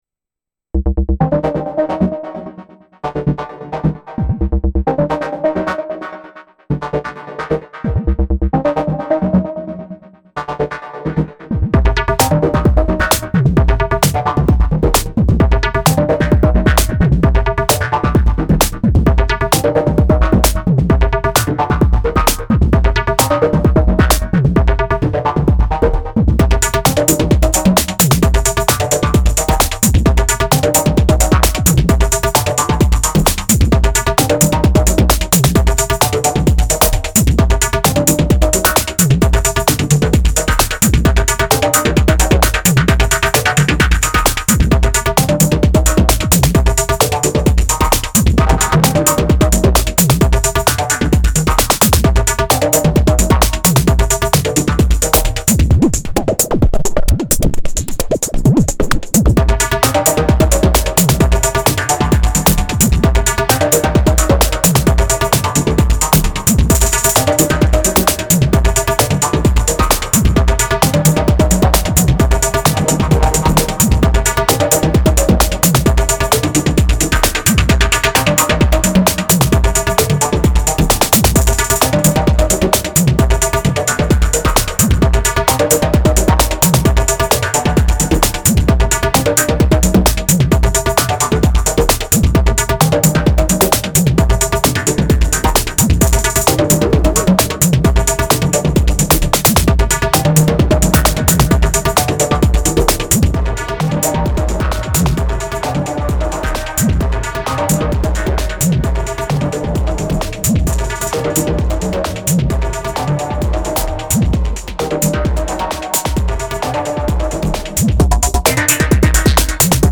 Single take, straight from the Syntakt, no post-processing.
Everything smashed through the FX track with a solid dose of drive.